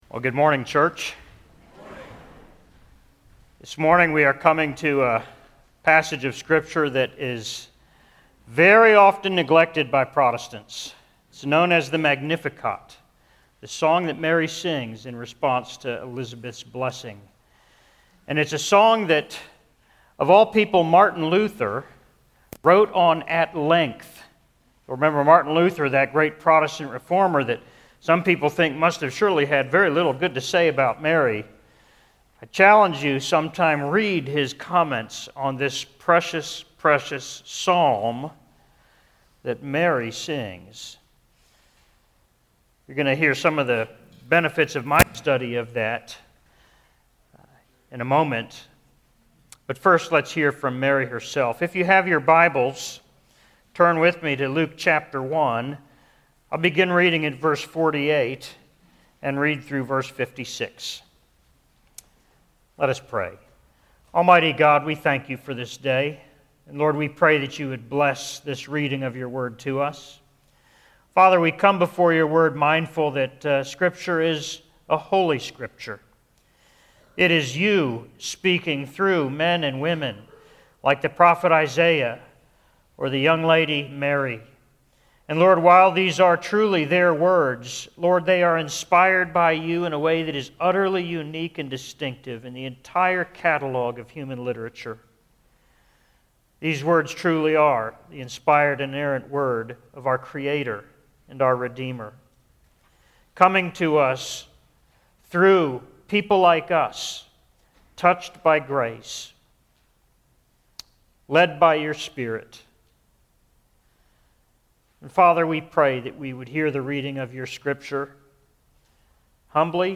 Sermon on Luke 1:46-56: Mary’s Song – Columbia Presbyterian Church